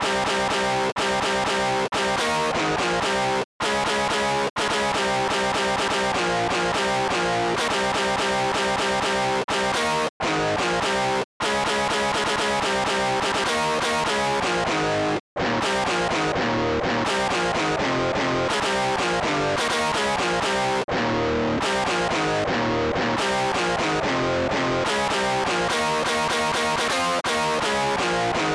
st-10:distguitar1 st-02:bassdrum3